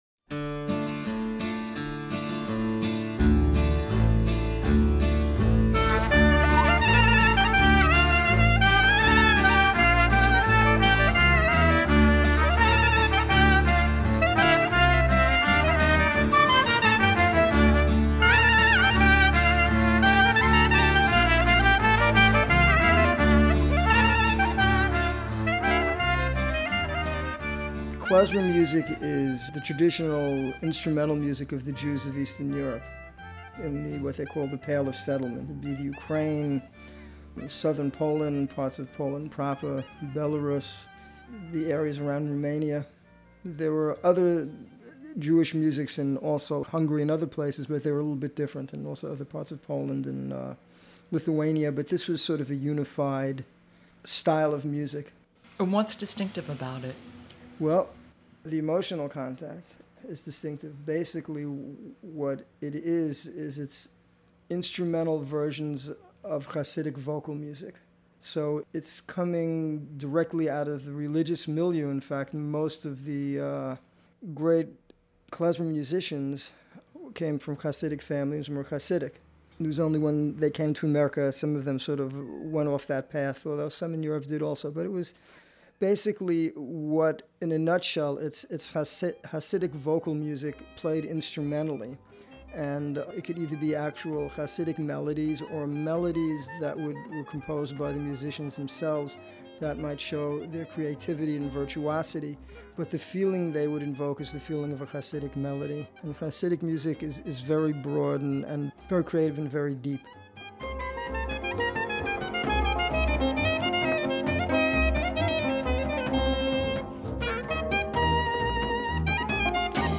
In this excerpt we hear Statman defining and playing Klezmer music.